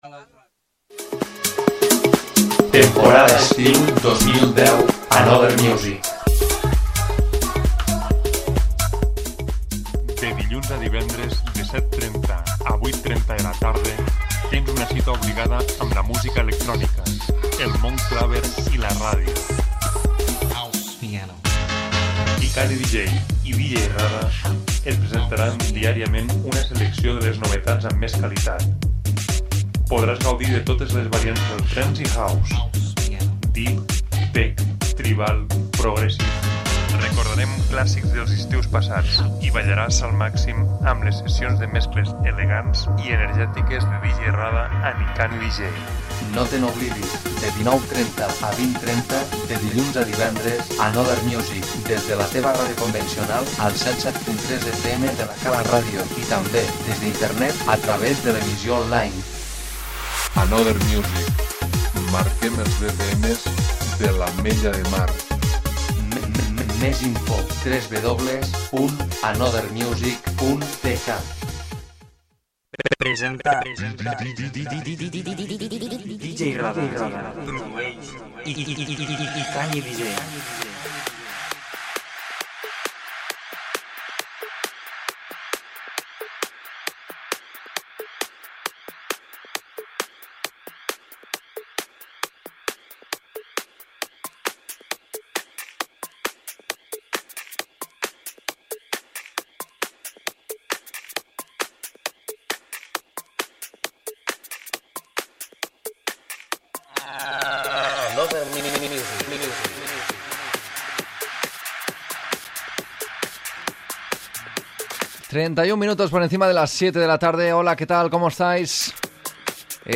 repàs de novetats Trance i House.